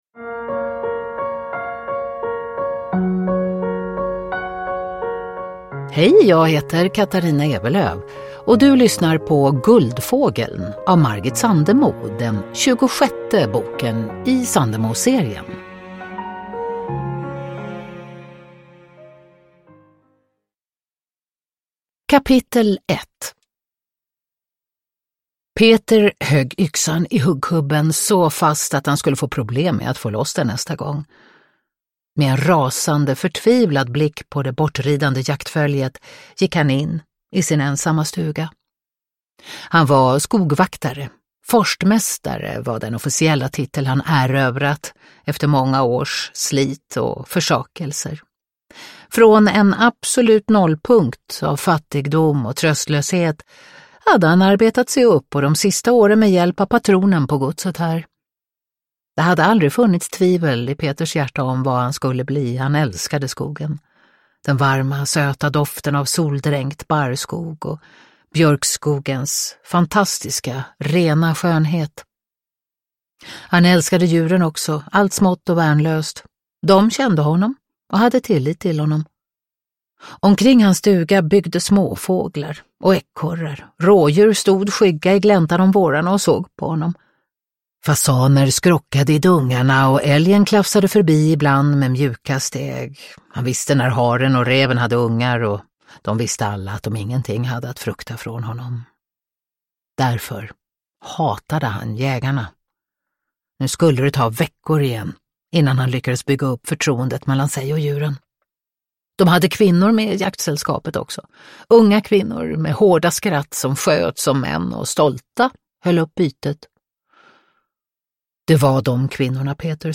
Guldfågeln – Ljudbok – Laddas ner
Sandemoserien är en unik samling fristående romaner av Margit Sandemo, inlästa av några av våra starkaste kvinnliga röster.
Uppläsare: Katarina Ewerlöf